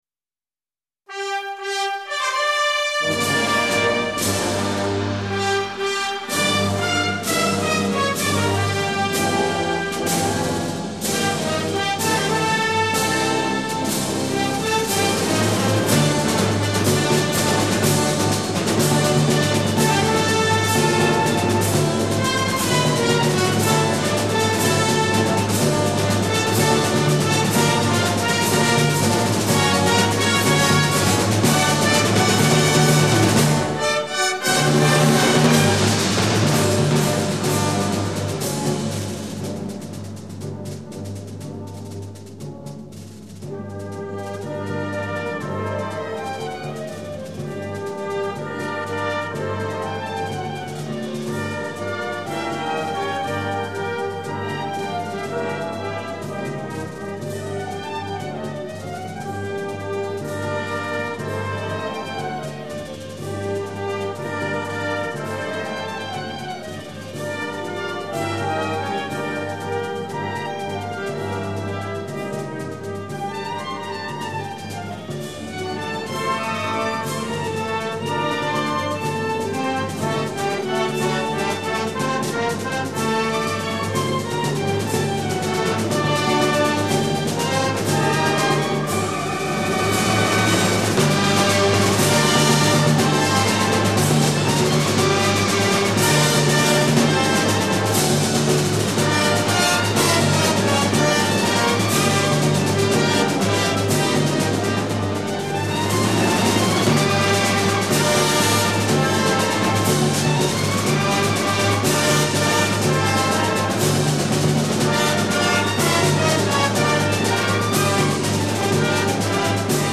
marcha mora